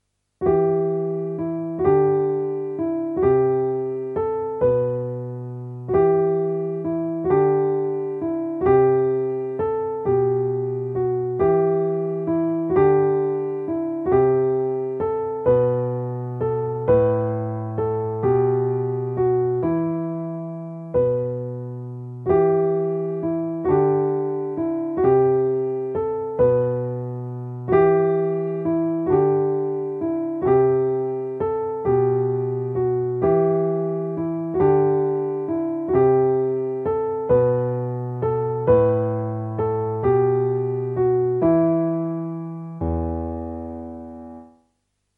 Besetzung: Klavier
Untertitel: 43 sehr leichte Klavierstücke ohne Achtel